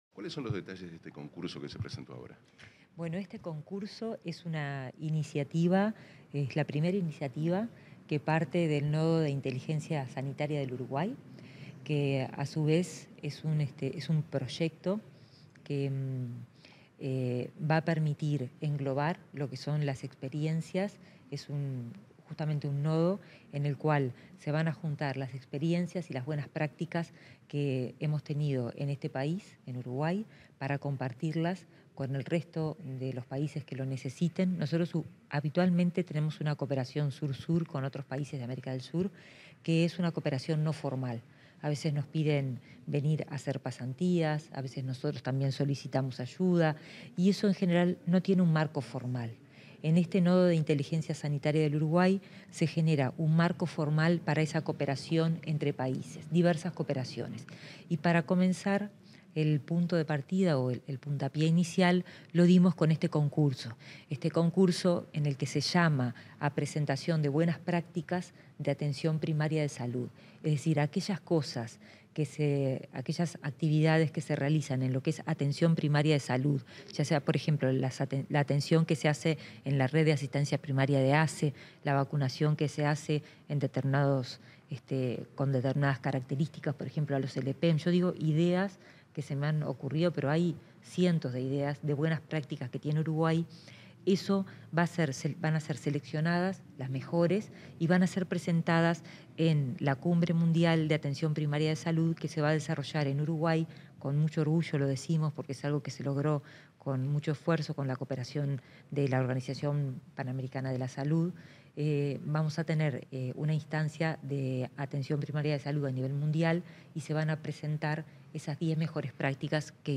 Declaraciones de la ministra de Salud Pública, Karina Rando
Tras el lanzamiento del concurso de buenas prácticas en atención primaria de salud, la ministra de Salud Pública, Karina Rando, realizó declaraciones